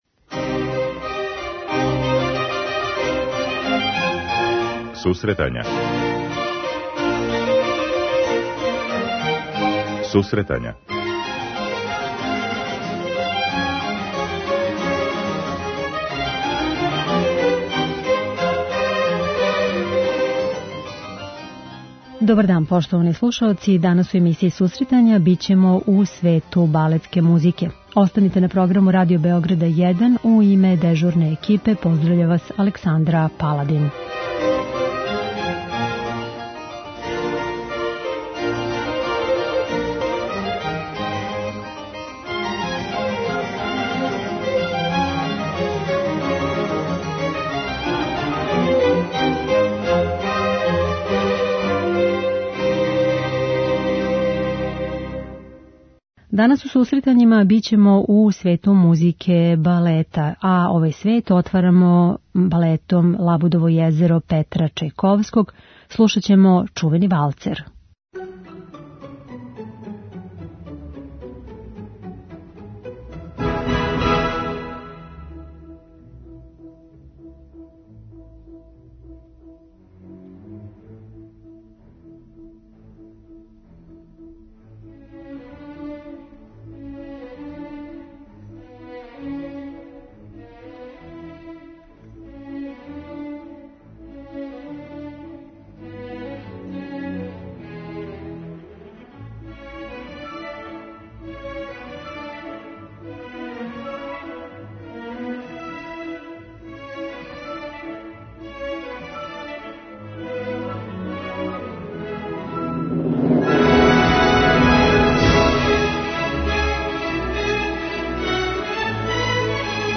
Свет балетске музике увек буди емоције, па је и избор музике у данашњој начињен да подстакне лепа сећања. Слушаоци ће бити у прилици да чују одломке из балета 'Лабудово језеро', 'Копелија', 'Бајадера', 'Дон Кихот' и 'Крцко Орашчић'.